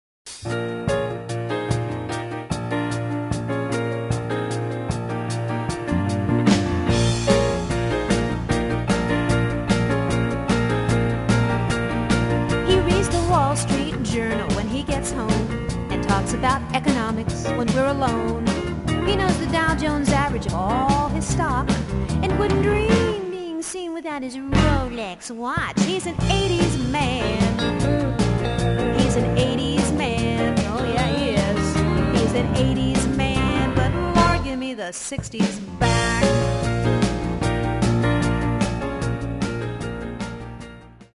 --humorous acoustic music